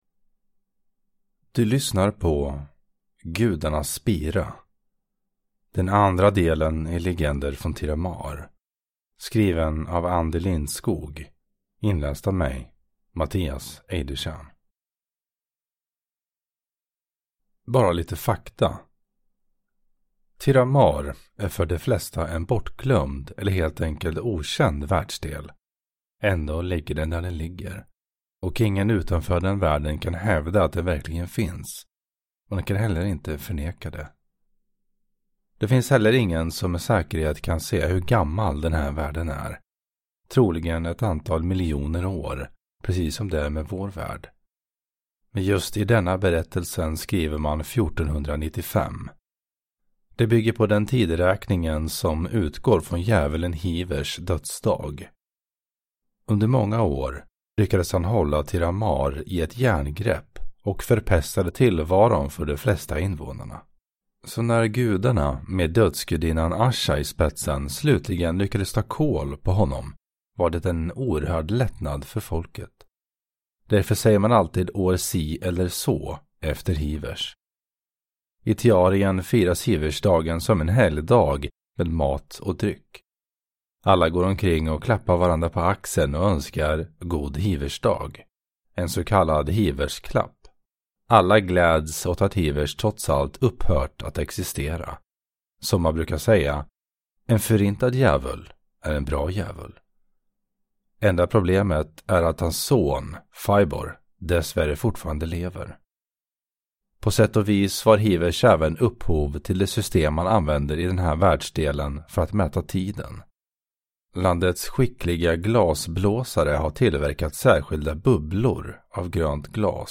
Gudarnas spira – Ljudbok